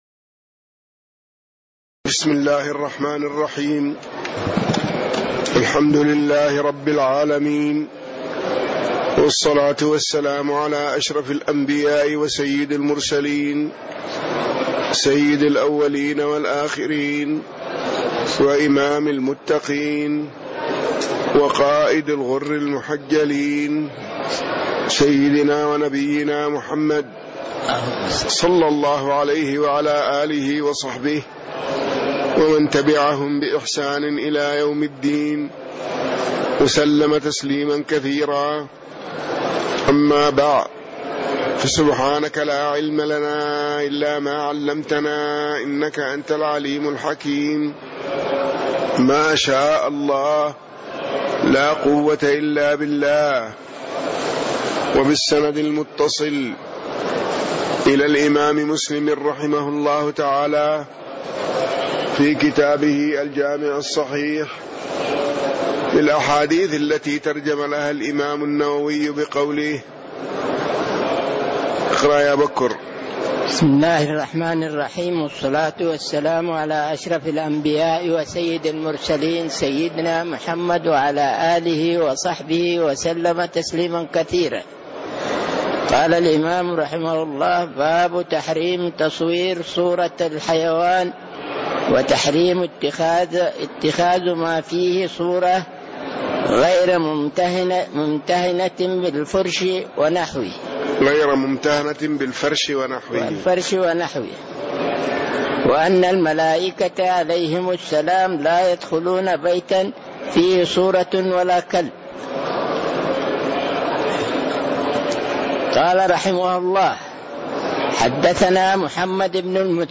تاريخ النشر ١٩ شوال ١٤٣٦ هـ المكان: المسجد النبوي الشيخ